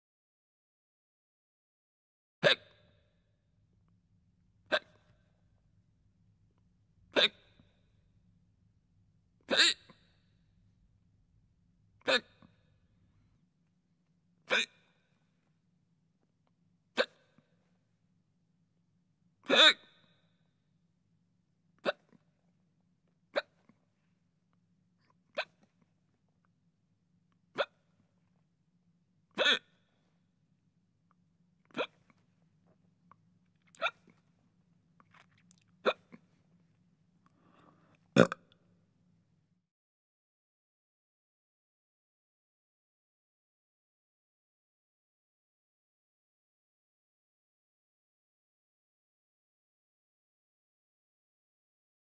دانلود آهنگ سکسکه 1 از افکت صوتی انسان و موجودات زنده
دانلود صدای سکسکه 1 از ساعد نیوز با لینک مستقیم و کیفیت بالا
جلوه های صوتی